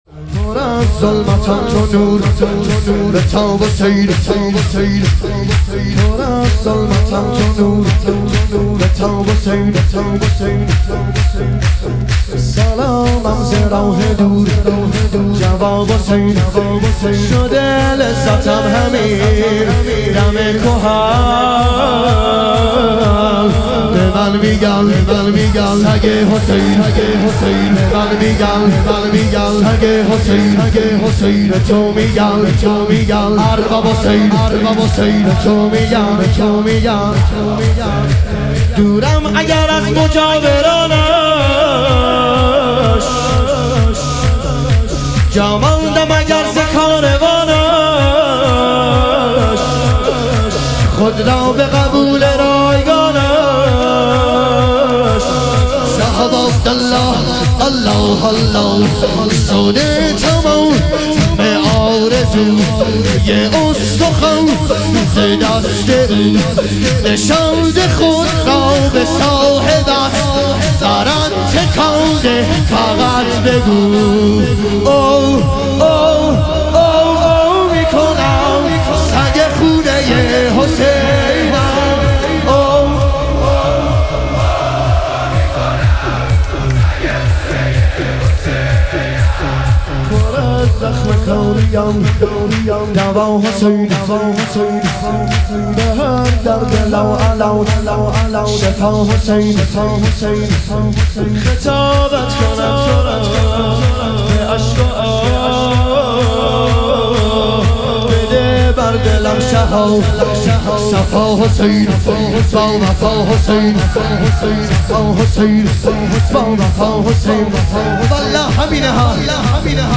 شهادت امام صادق علیه السلام - شور - 7 - 1404